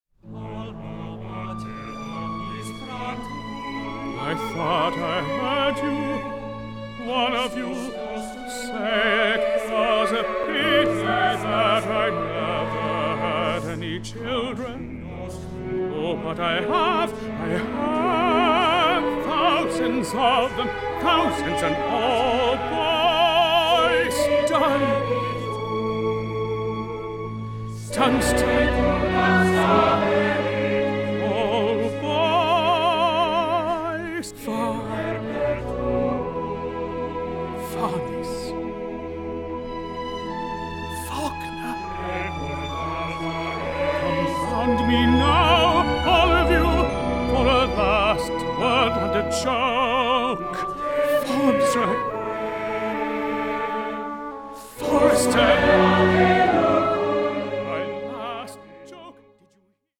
Film Soundtrack of the Opera